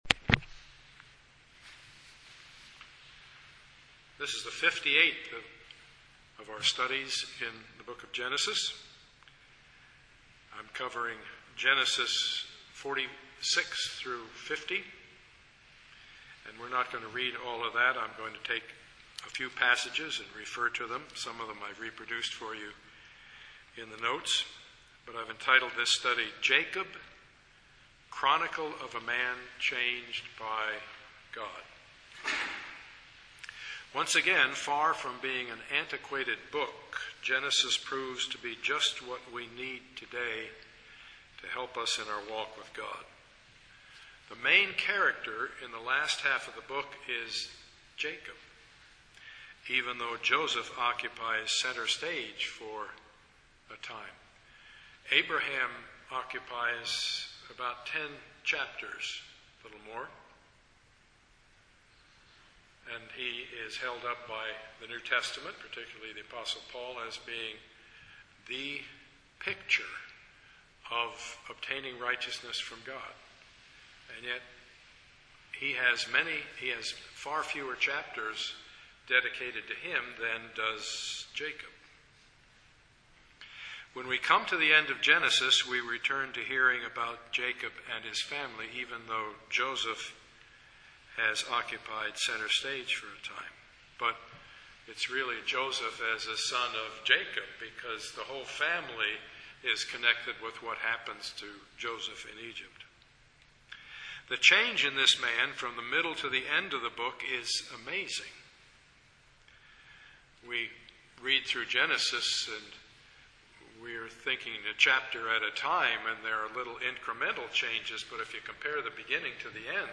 Passage: Genesis 46-50 Service Type: Sunday morning
Sermon Series